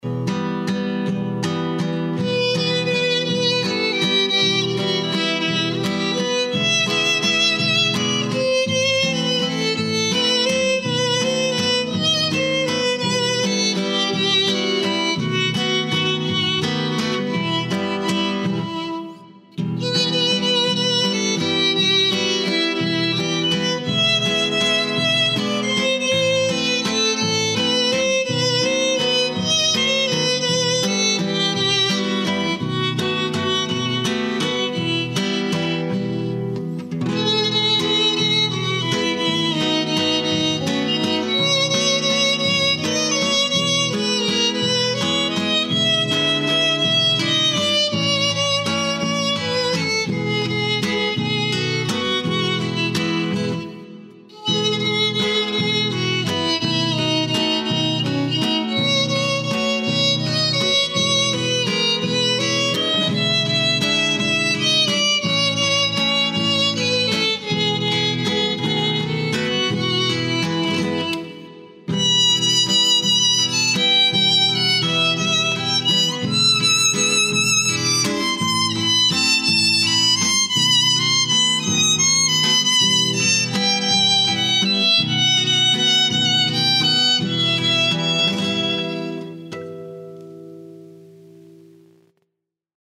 Las interpretaciones han sido sobre la marcha, de hecho él ni conocía algunas de ellas, de ahí su gran destreza para improvisarlas de un modo tan rápido.
Romance-anonimo-violin.mp3